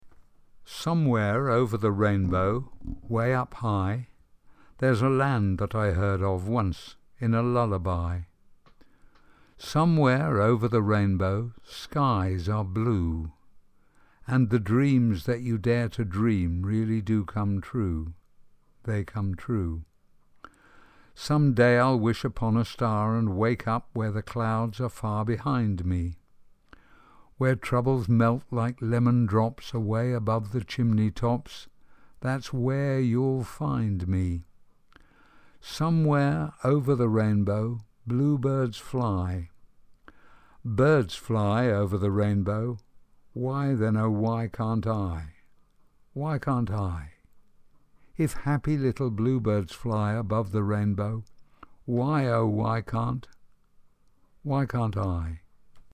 Fichiers de prononciation